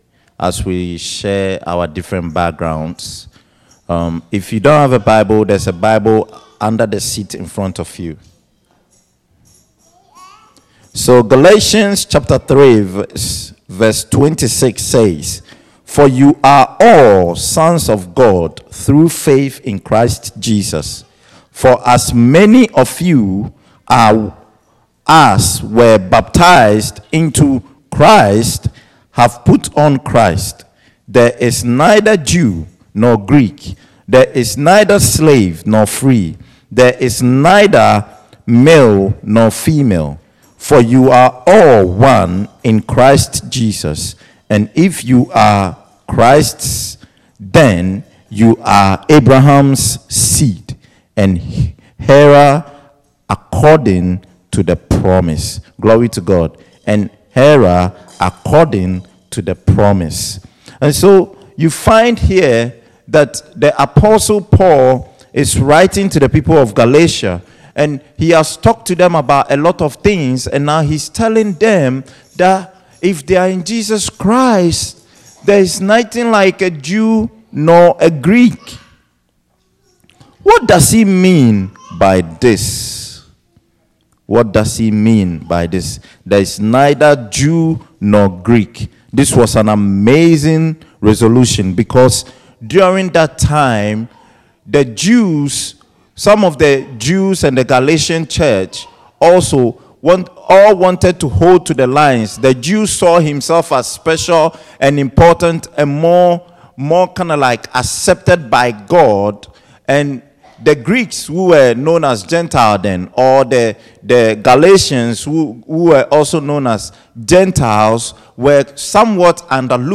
International-Service.m4a